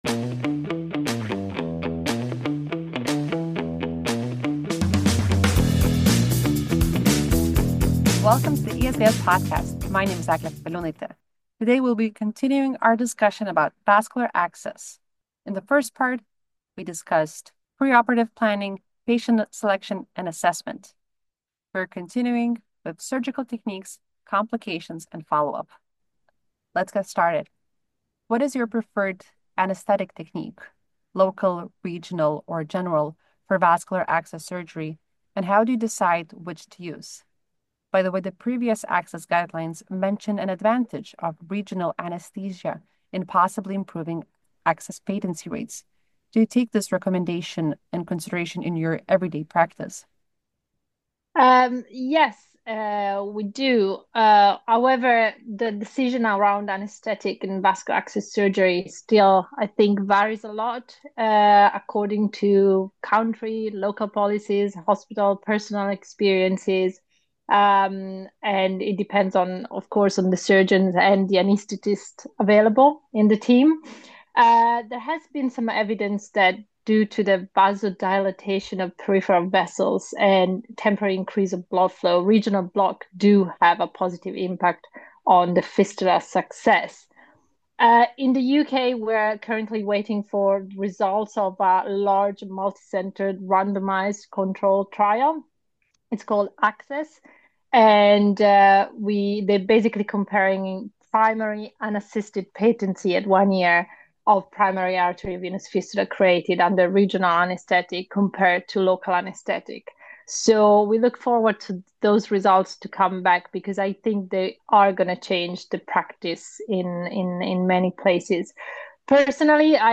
Today’s episode is a throwback to ESVS Annual meeting in Krakow.